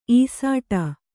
♪ īsāṭa